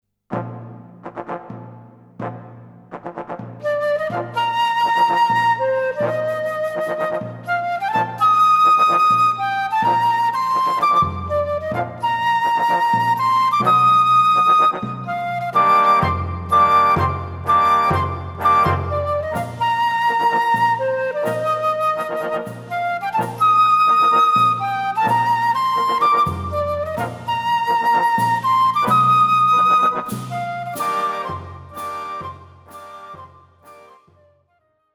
フルート+ピアノ